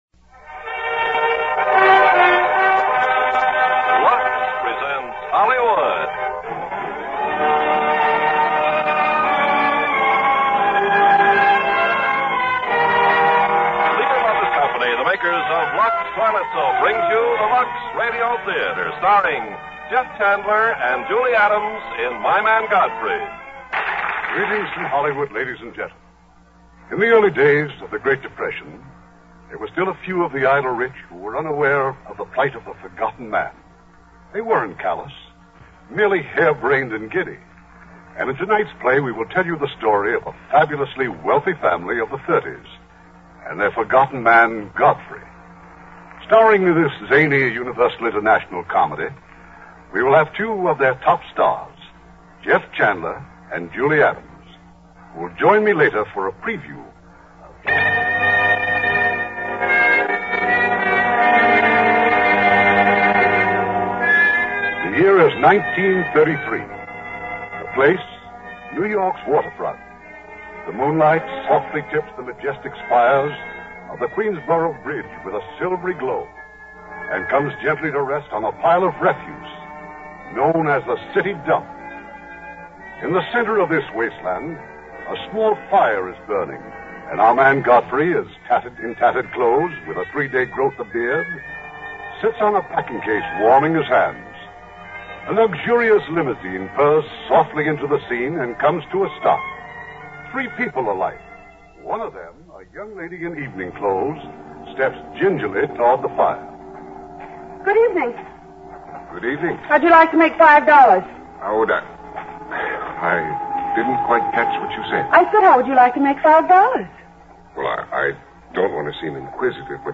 starring Jeff Chandler, Julie Adams
Lux Radio Theater Radio Show